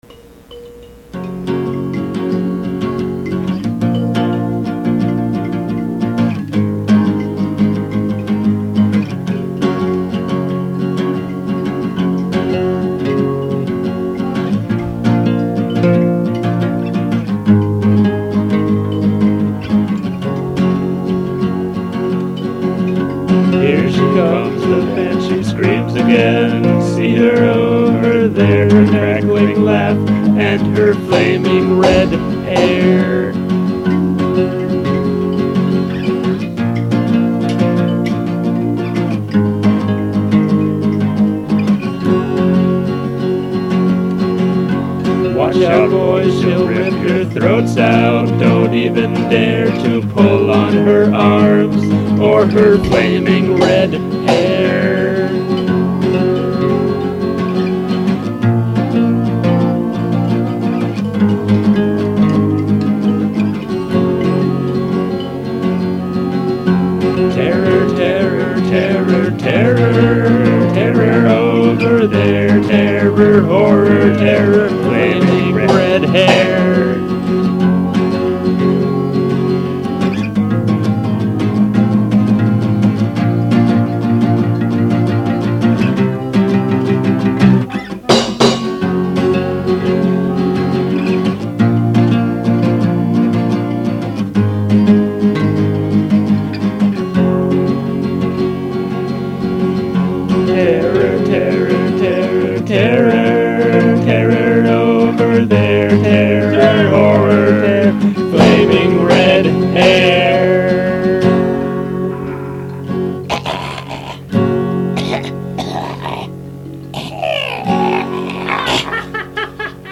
a decent little album featuring a number of fourtrack songs.